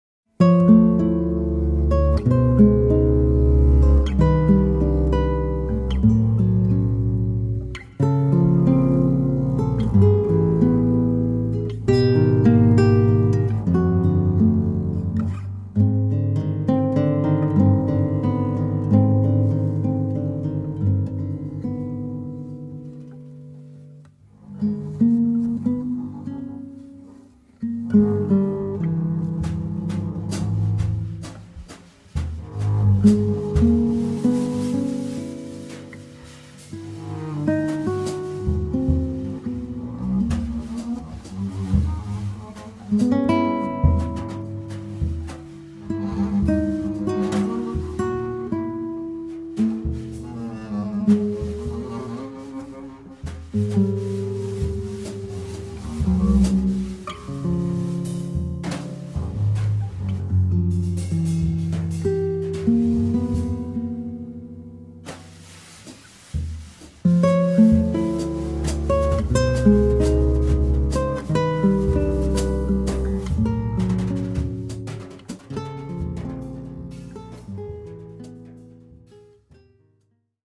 contrabbasso
chitarra acustica
percussioni
Registrato in presa diretta
le astrazioni quasi matematiche